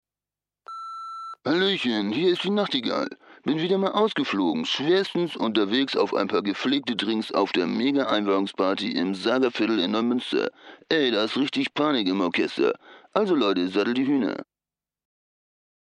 deutscher Stimmenimitator, Sprecher, Entertainer, Musiker..
Sprechprobe: Werbung (Muttersprache):